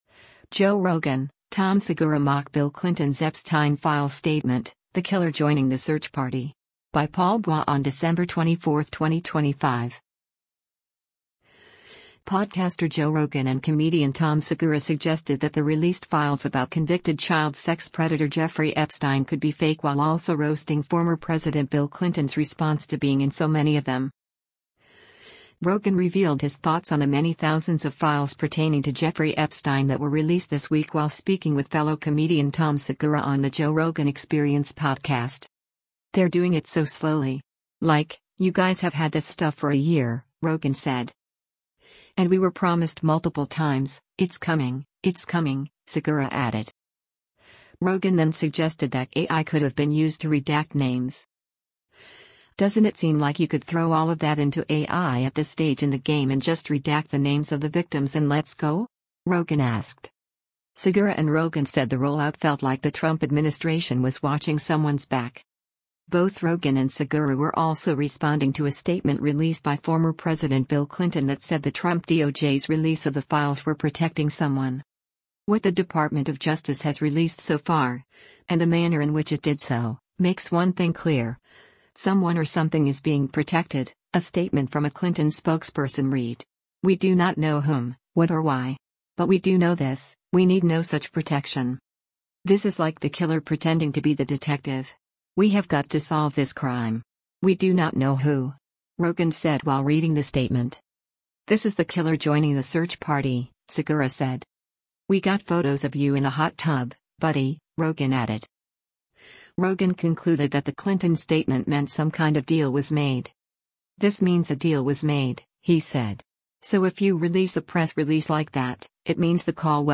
Rogan revealed his thoughts on the many thousands of files pertaining to Jeffrey Epstein that were released this week while speaking with fellow comedian Tom Segura on The Joe Rogan Experience podcast.